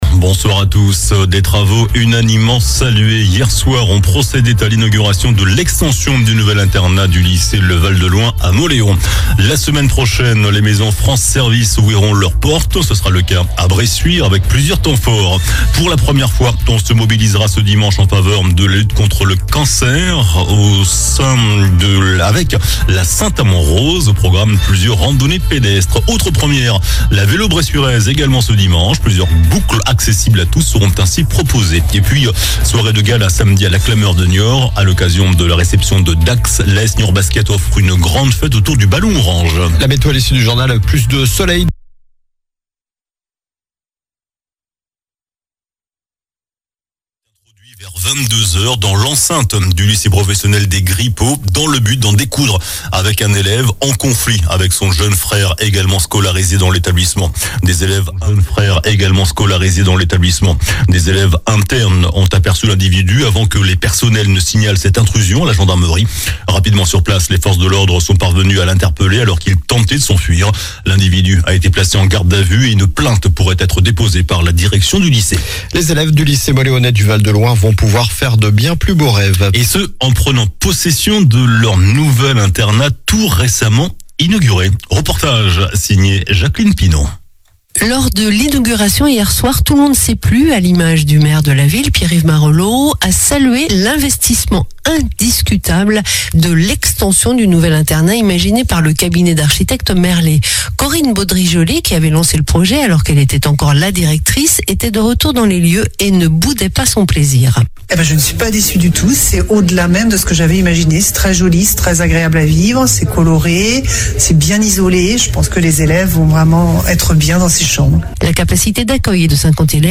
JOURNAL DU JEUDI 03 OCTOBRE ( SOIR )